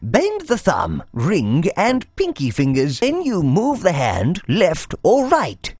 • Always mono for 3D and to save space
dialogue_pcm_mono_HELP_TEXT_TUTORIAL_LAND_26.wav